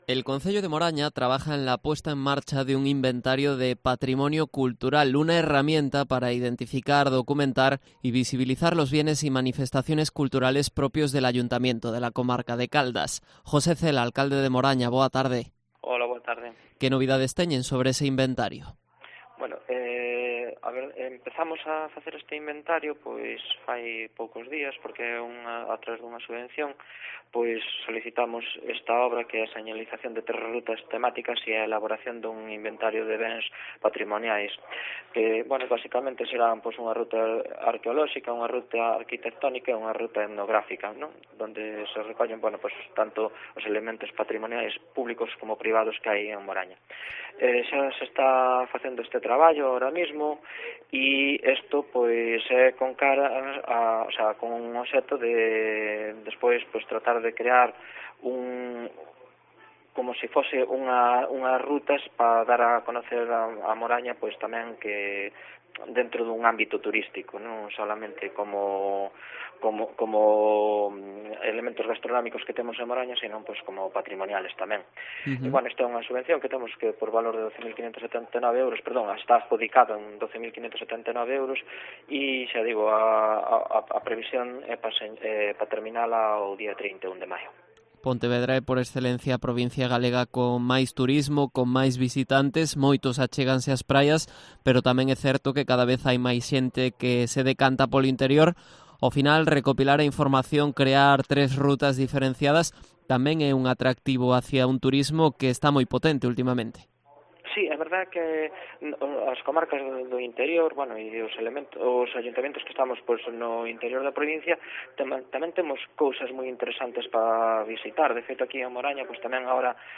Entrevista a José Cela, alcalde de Moraña